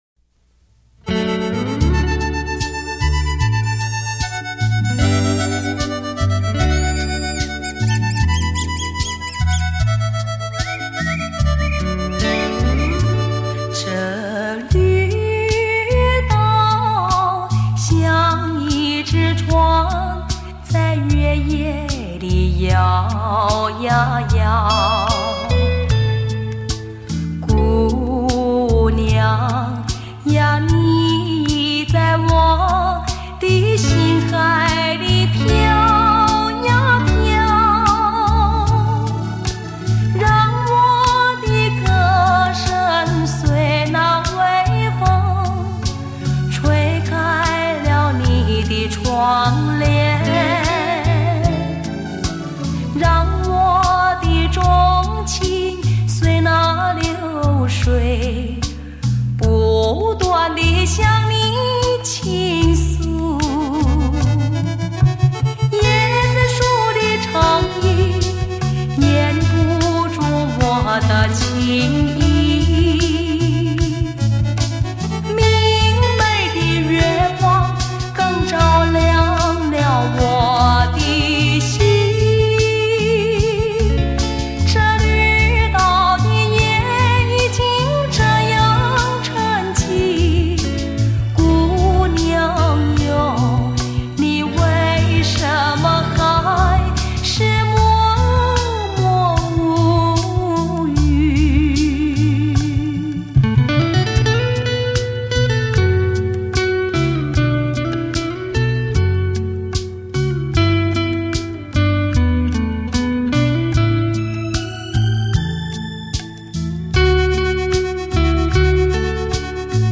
歌唱版
立体演唱会 环绕身历声